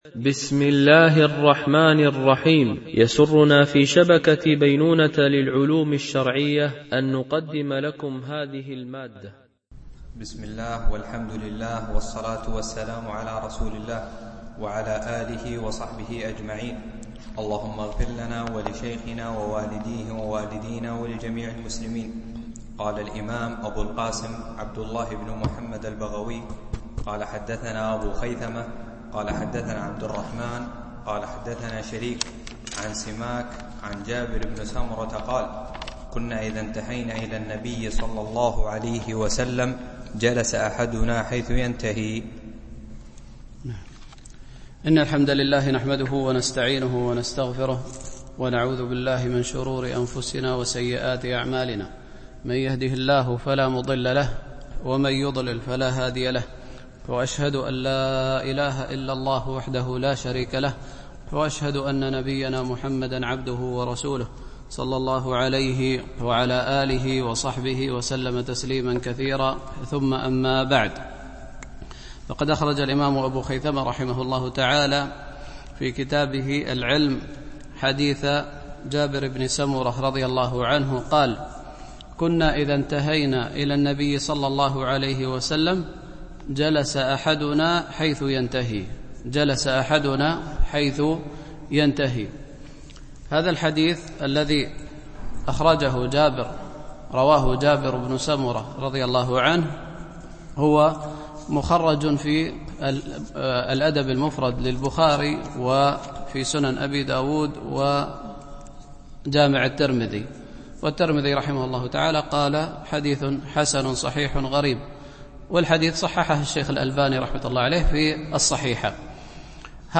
شرح كتاب العلم لأبي خيثمة ـ الدرس 33 (الأثر 100-103)